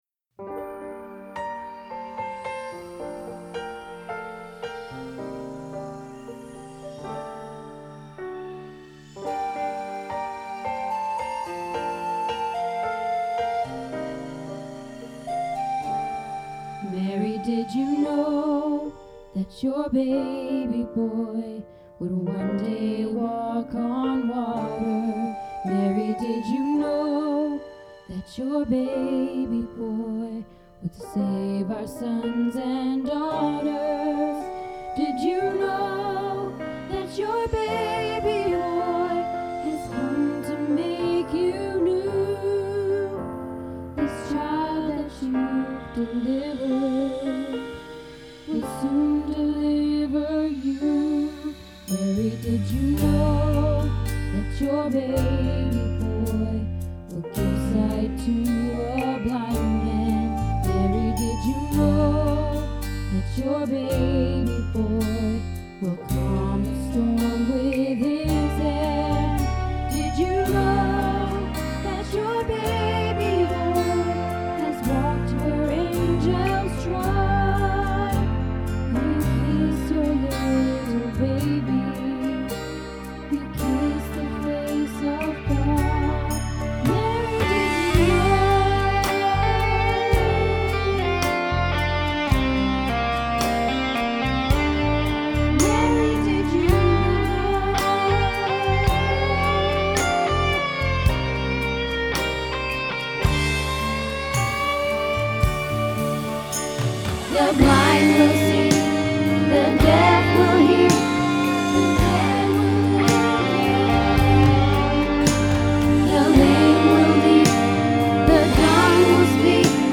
Mary Did You Know - Tenor